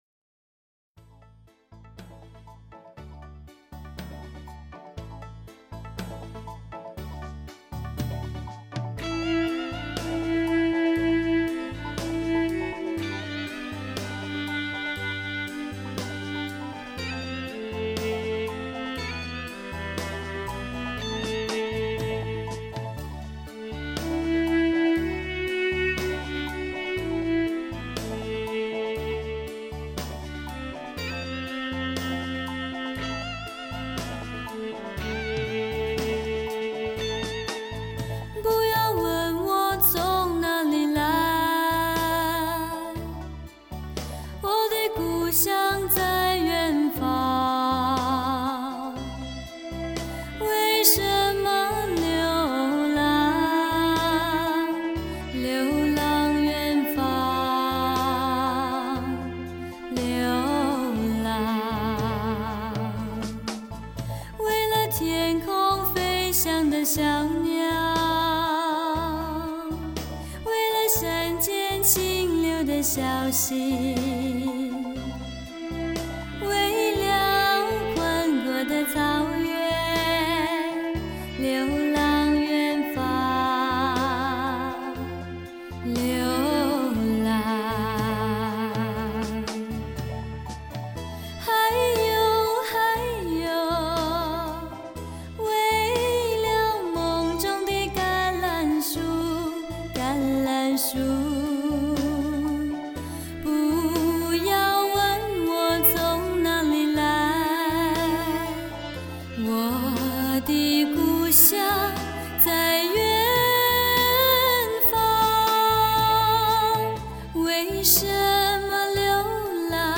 24bit/96khz的多轨数码录音，
保证了音色的清晰度和更宽阔的音域动态范围
让音乐的现场效果更细腻、逼真，音质更纯正
5.0声道的数码输出，全面超越传统CD（2声道）的音效